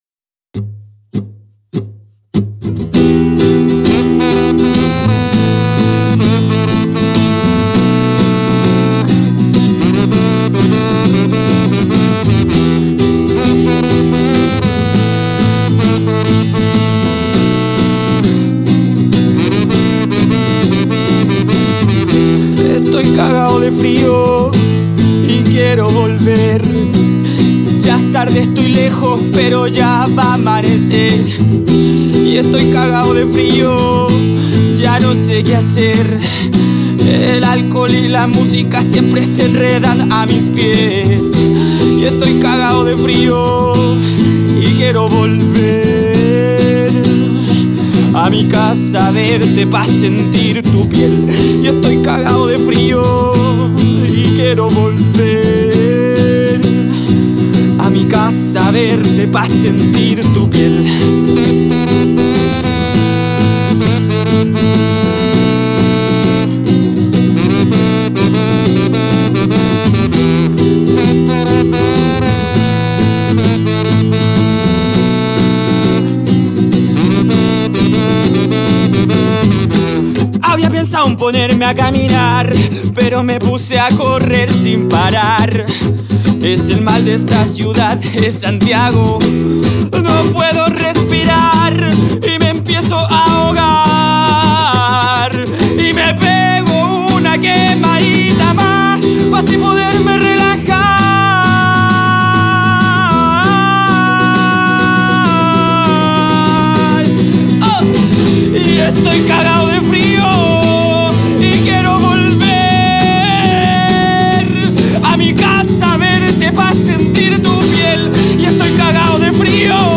una canción intensa y a la vez muy real